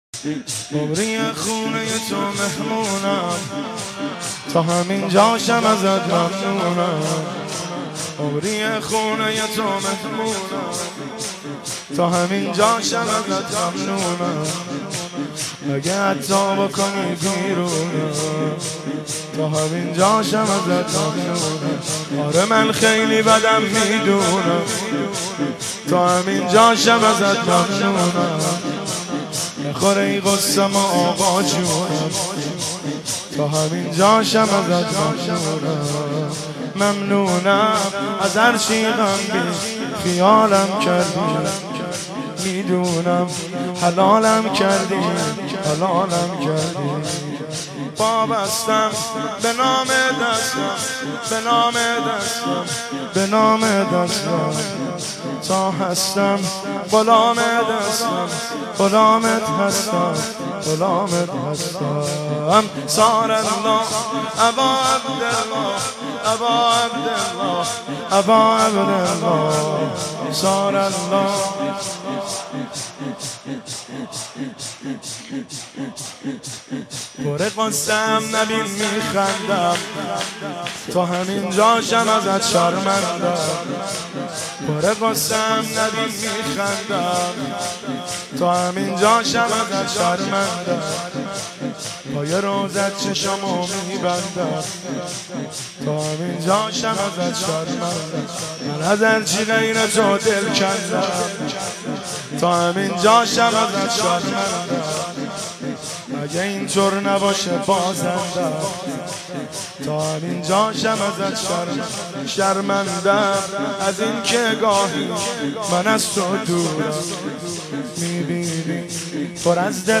رمضان 1393
هیئت بین الحرمین